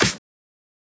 edm-clap-57.wav